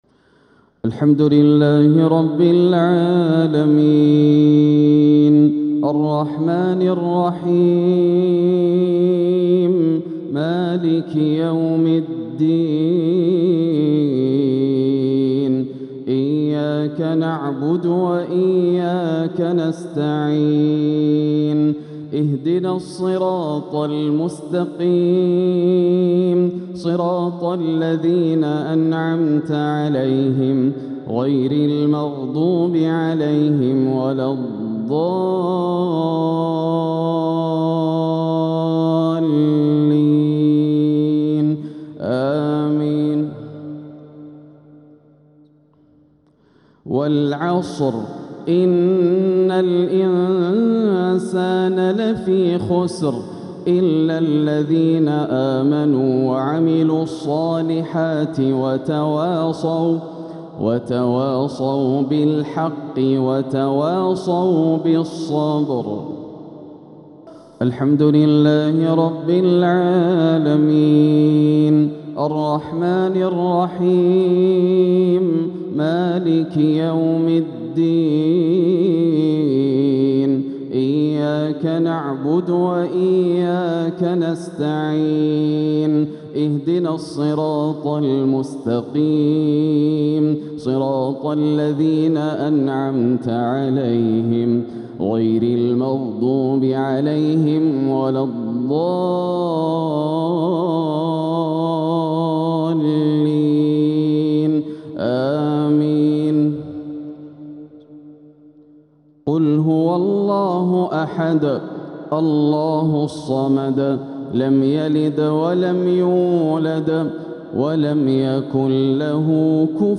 تلاوة لسورتي العصر والإخلاص | مغرب الخميس 3-8-1447هـ > عام 1447 > الفروض - تلاوات ياسر الدوسري